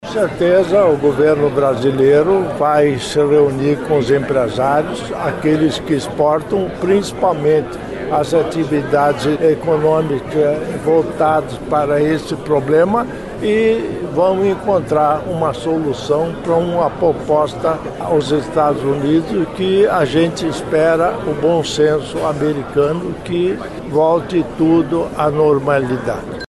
Darci Piana disse que o Paraná pode colaborar nas reuniões que o governo federal vai fazer com os estados para resolver esse impasse.